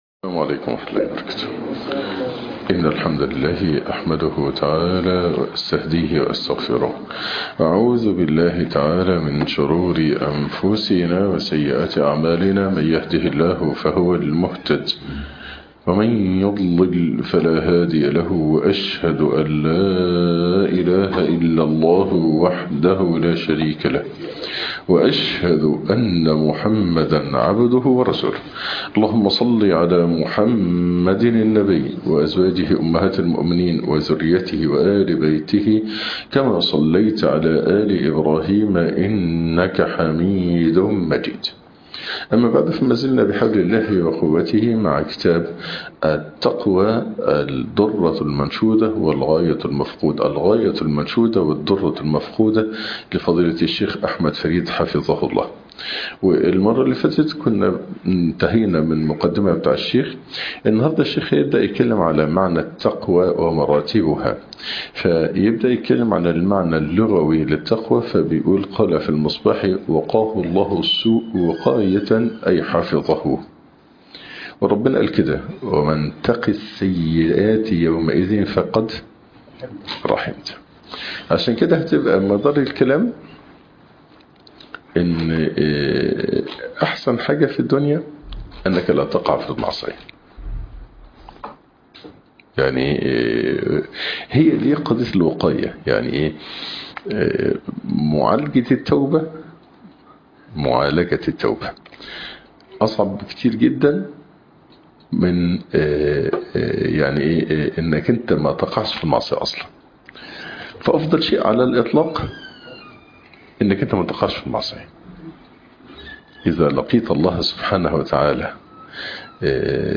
( شرح كتاب التقوى ) الدرس الثاني